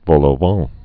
(vôlō-väɴ)